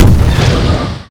rpgShoot.ogg